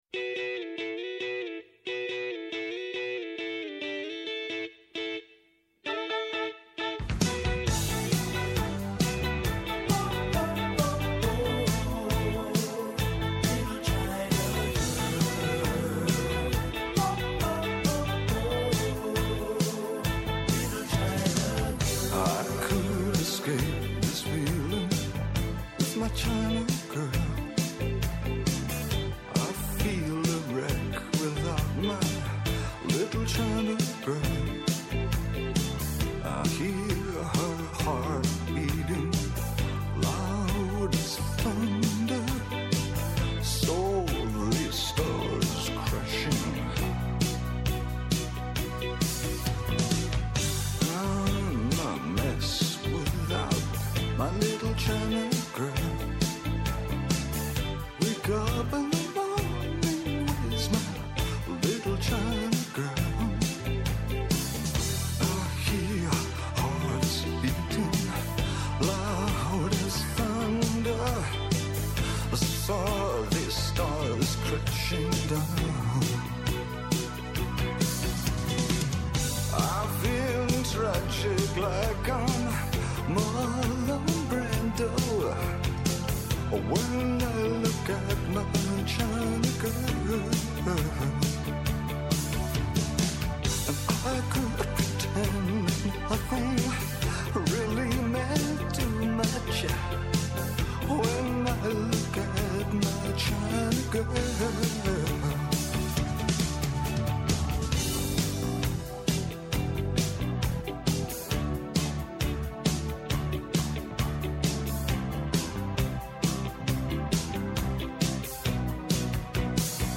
Kαλεσμένοι στην εκπομπή είναι κυβερνητικοί αξιωματούχοι, επιχειρηματίες, αναλυτές, τραπεζίτες, στελέχη διεθνών οργανισμών, πανεπιστημιακοί, φοροτεχνικοί και εκπρόσωποι συνδικαλιστικών και επαγγελματικών φορέων, οι οποίοι καταγράφουν το σφυγμό της αγοράς και της οικονομίας.